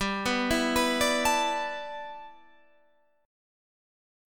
G6add9 chord